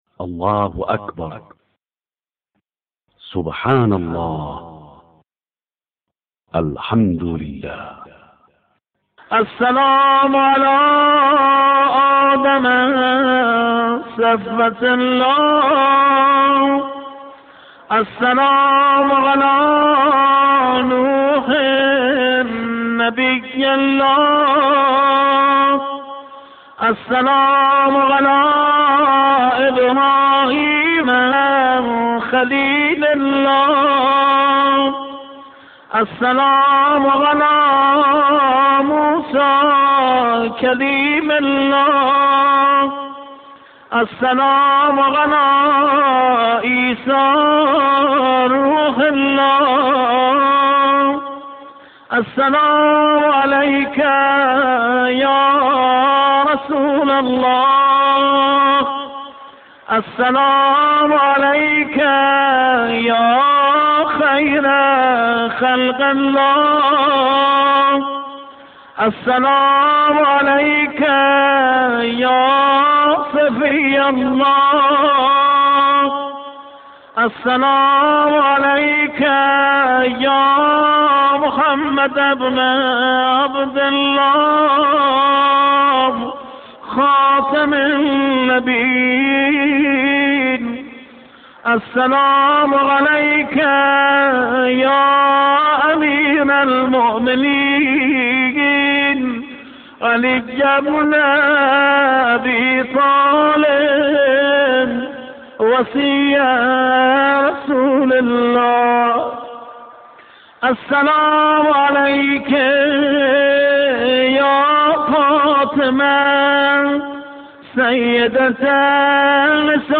به مناسبت شب میلاد حضرت فاطمه معصومه(س) نوای زیارتنامه آن حضرت با متن این دعا ارائه می‌شود.